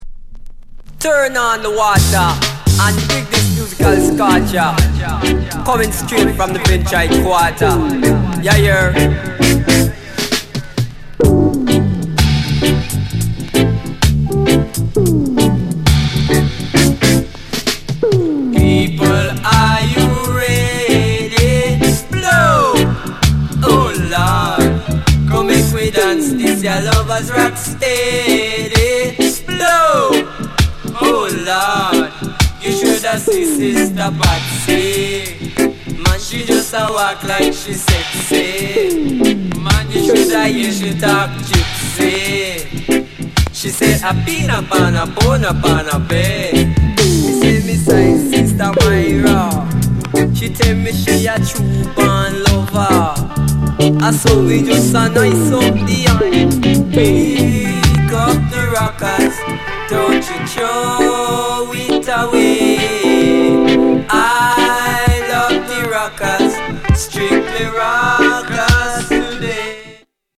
NICE LOVERS ROCK